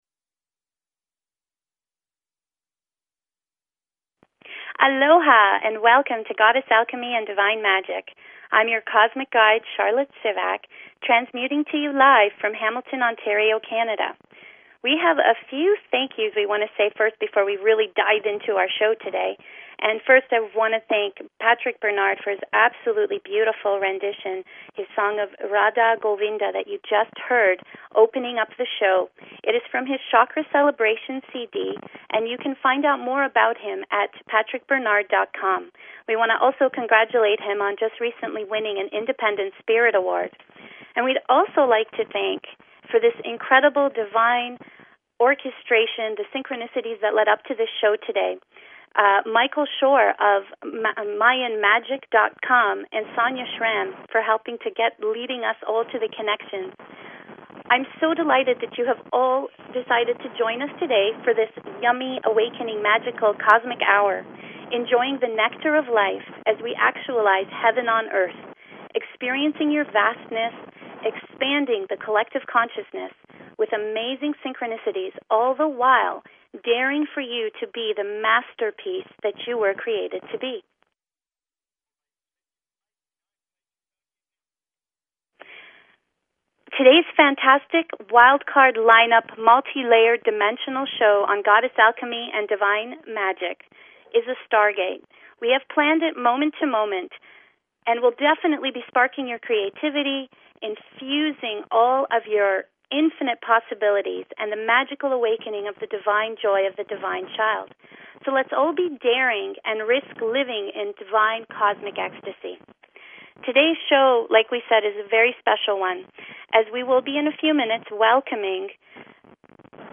Show 12 of Goddess Alchemy and Divine Magic on BBS Radio!